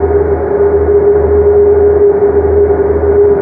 teleport_idle.wav